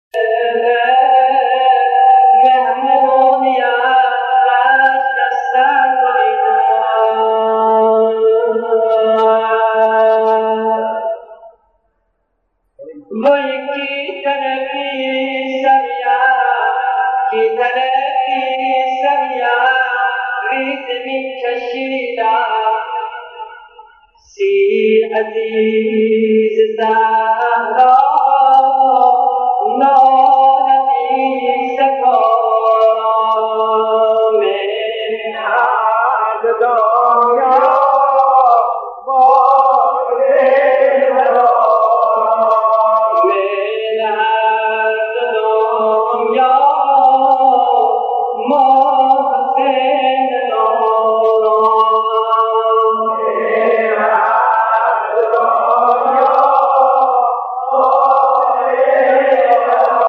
صوت مارضایی خوانی